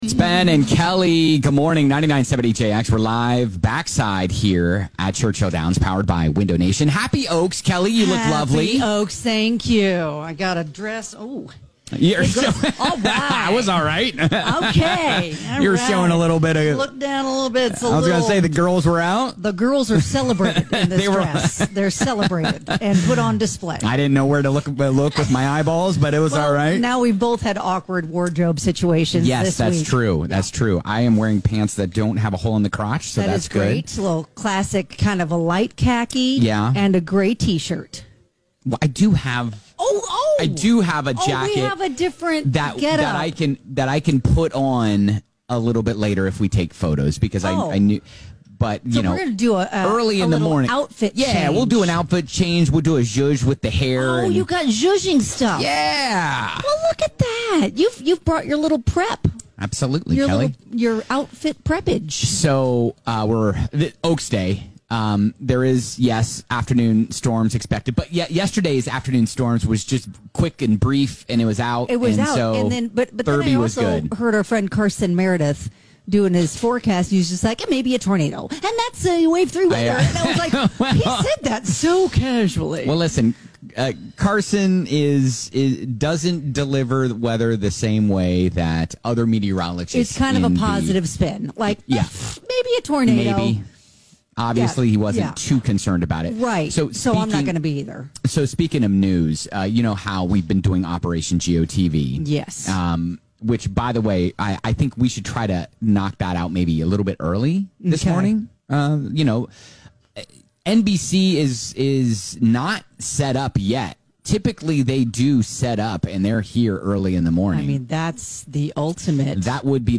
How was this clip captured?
Last day at the track, we brought our fancy pants and go full steam ahead on Operation GOTV, even getting called out on live TV! Plus Oaks Jokes and more!